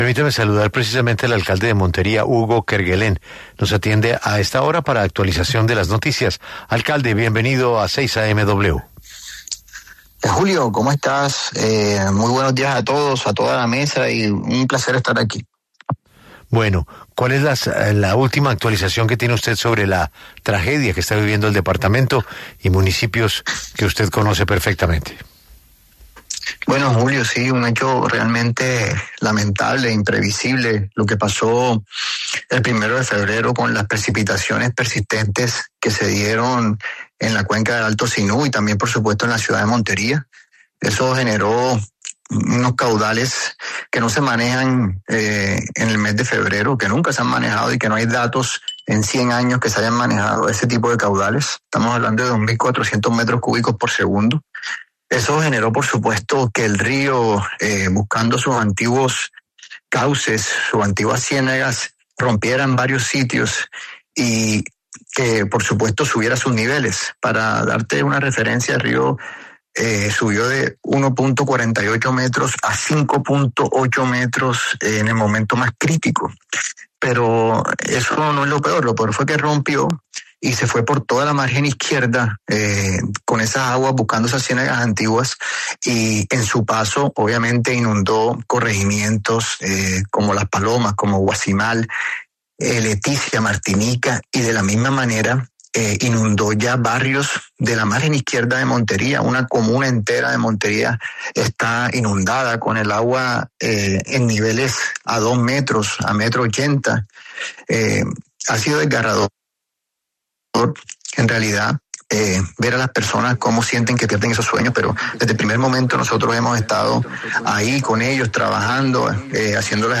En entrevista con 6AM W y Julio Sánchez Cristo, el alcalde de Montería, Hugo Kerguelén, dio los detalles de la emergencia en su ciudad que ha sido la más afectada por esta emergencia climática.